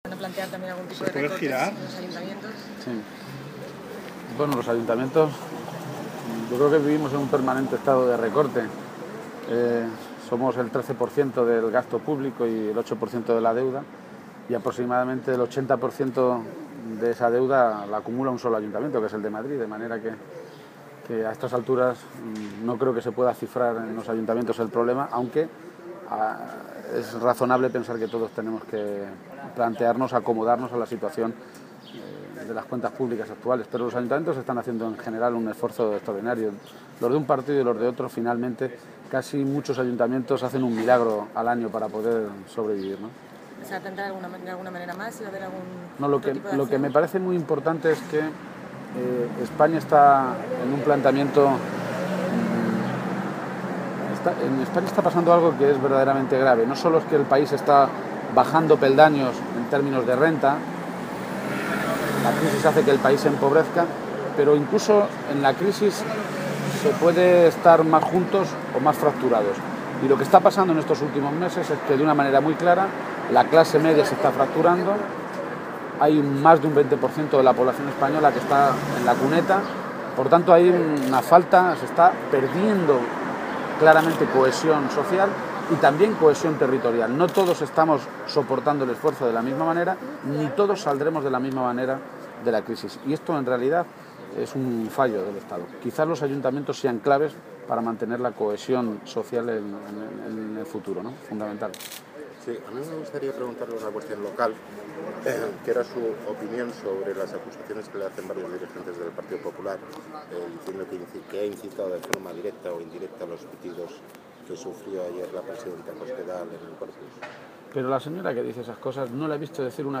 Emiliano García Page ha realizado estas declaraciones en Madrid antes de participar en el encuentro que el secretario general del PSOE, Alfredo Pérez Rubalcaba, ha mantenido con alcaldes, portavoces municipales, secretarios regionales de Política Municipal, Presidentes de Diputaciones, Cabildos y Consells y representantes de la FEMP, con motivo del primer año de la constitución de los Ayuntamientos fruto de las elecciones municipales del 22 de mayo de 2011.